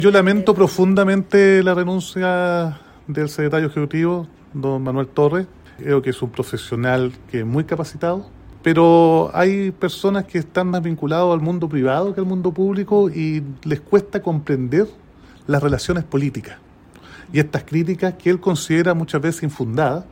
cuna-amzoma-alcalde.mp3